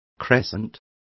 Complete with pronunciation of the translation of crescent.